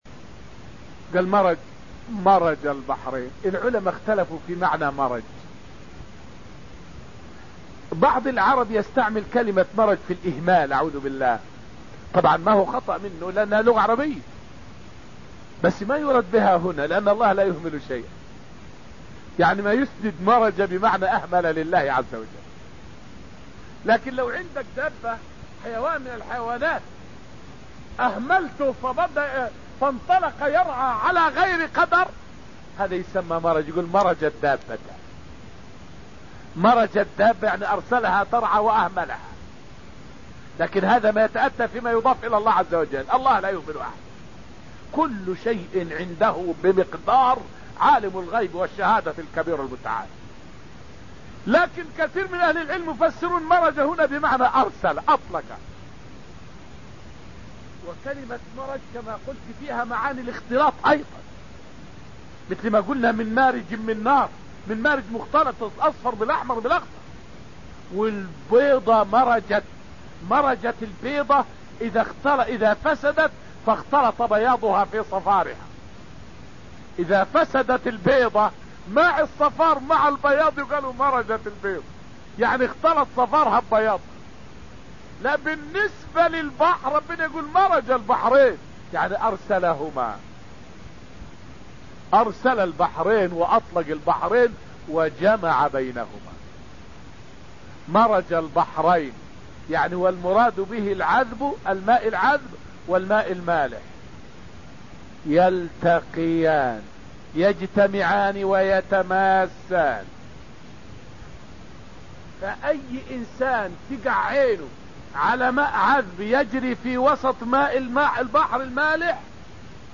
فائدة من الدرس التاسع من دروس تفسير سورة الحشر والتي ألقيت في المسجد النبوي الشريف حول الموالاة بين المنافقين واليهود.